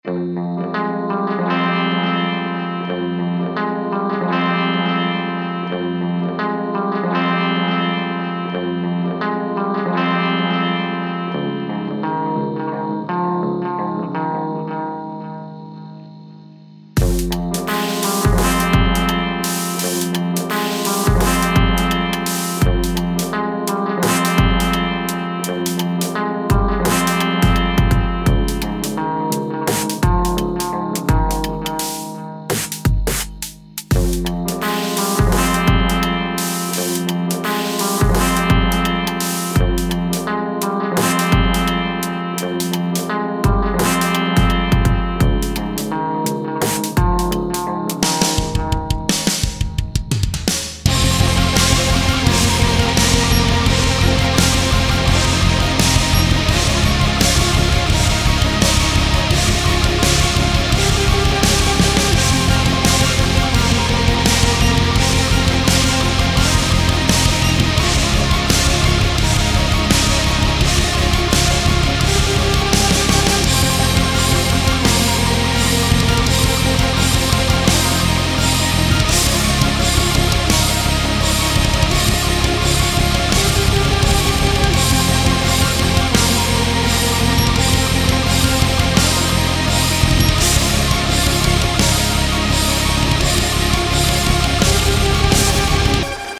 I recorded it at home using standard sound card and jack-jack cable linked to my guitar amp. Drums and bass are programmed in VST plugins.